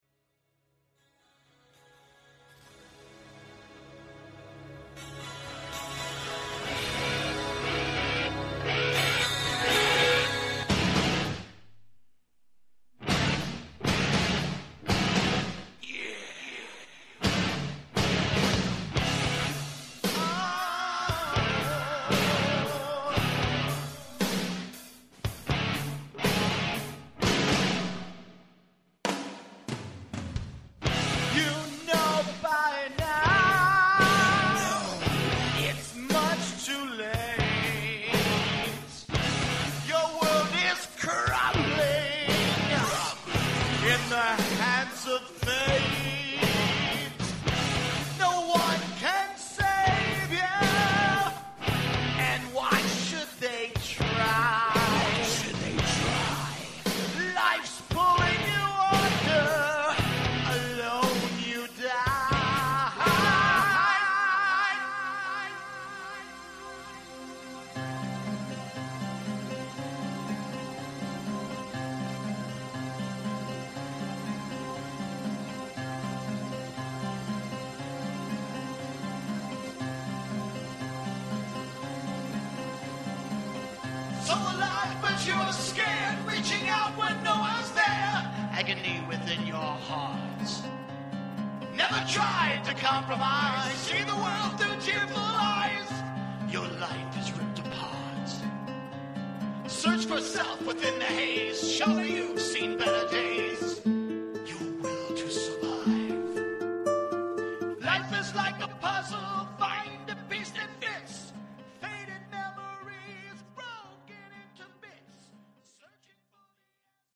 Heavy metal
Prog rock